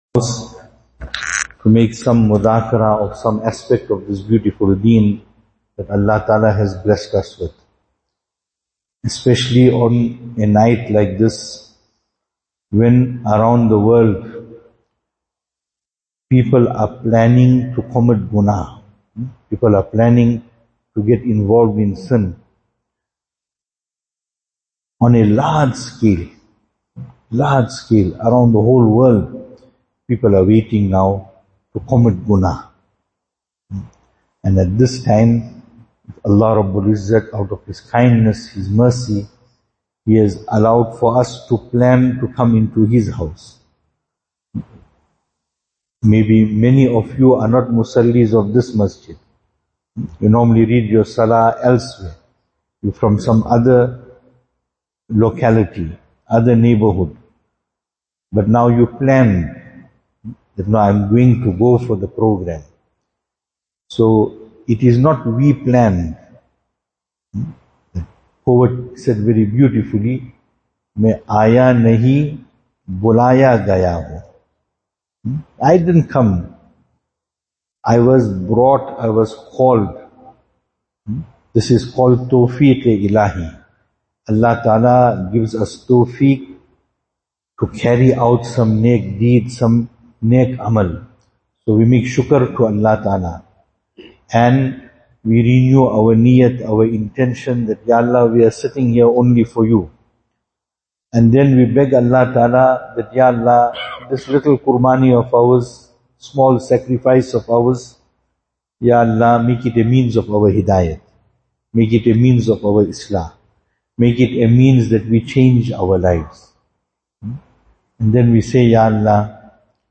Programme from Siraatul Jannah, Ormonde.
Venue: Masjid Siraatul-Jannah , Ormonde Series: Johannesburg Safr Service Type: Out-Program « Incident regarding Hadhrat Baaqibillah Rahmatullahi Alaih and the female slave.